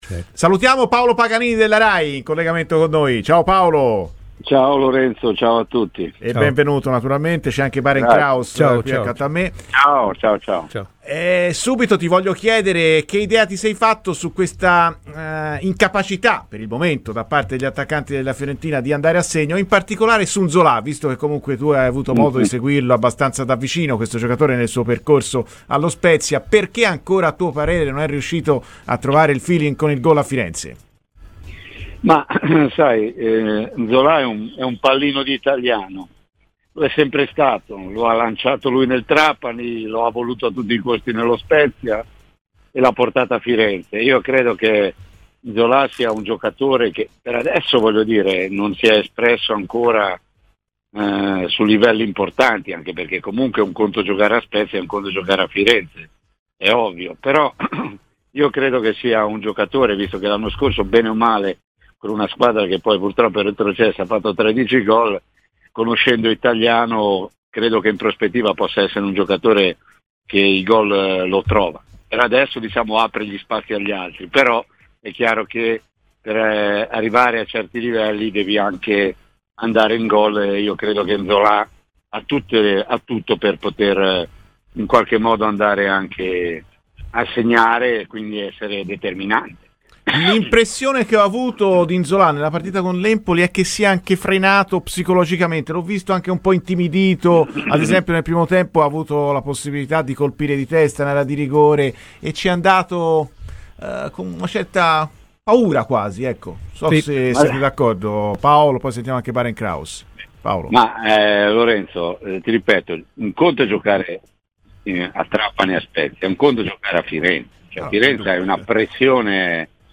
è intervenuto a Radio FirenzeViola nel corso della trasmissione Viola Amore Mio .